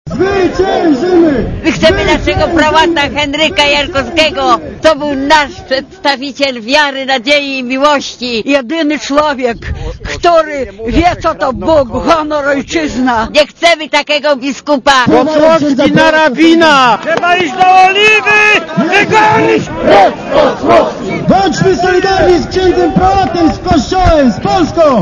* Posłuchaj, co wykrzykiwali zwolennicy prałata na wiecu*
brygida-wiec_poparcia.mp3